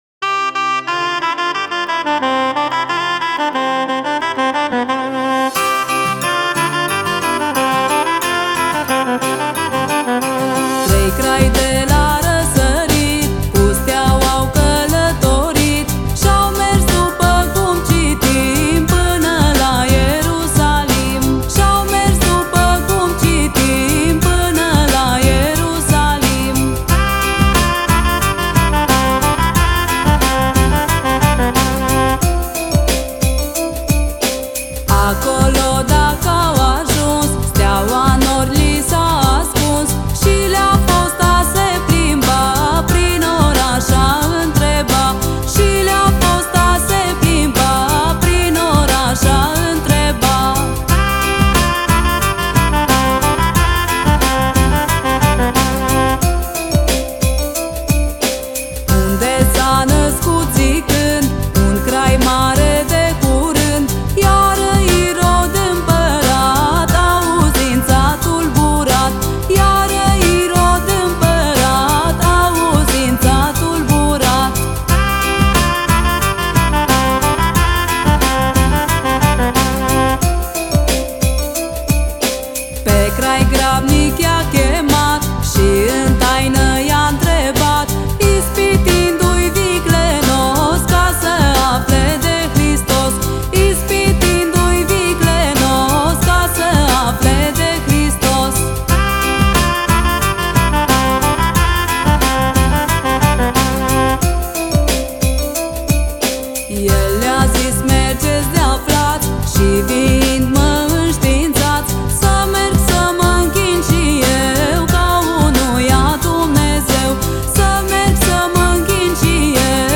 Colinde de Craciun